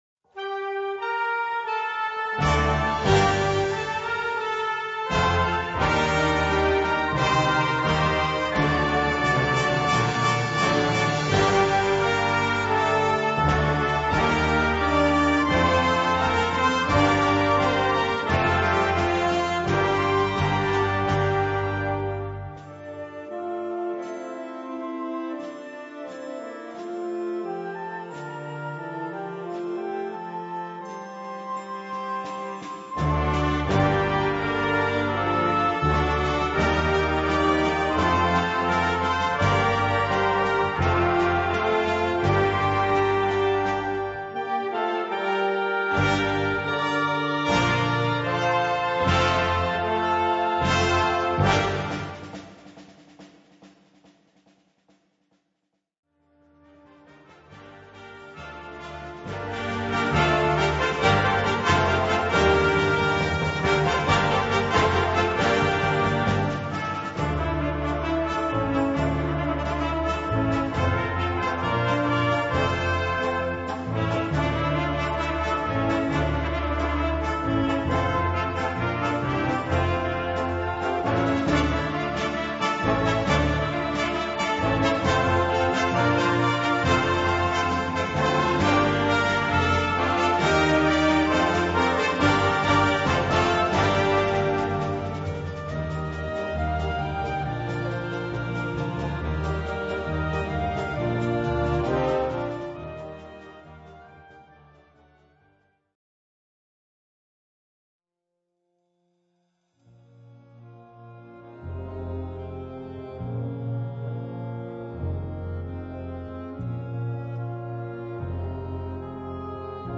Categorie Harmonie/Fanfare/Brass-orkest
Subcategorie Ouverture (originele compositie)
Bezetting Ha (harmonieorkest)